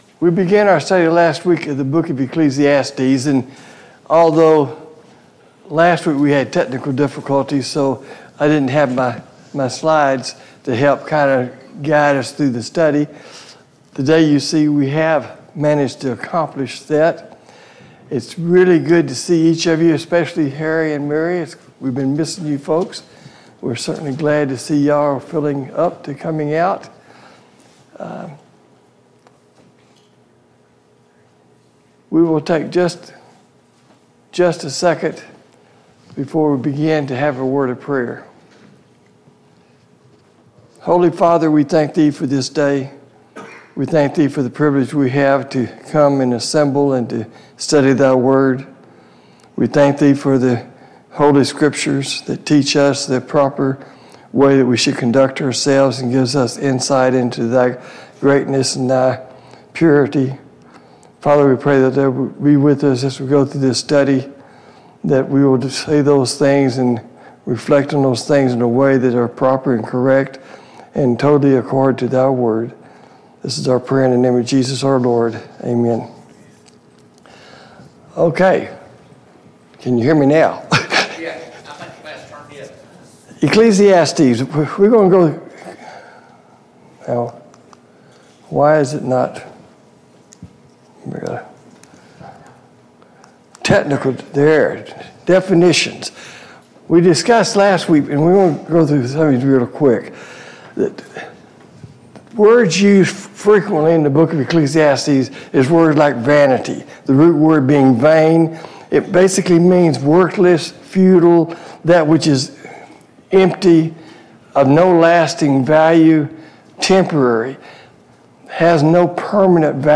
Sunday Morning Bible Class Download Files Notes « 35.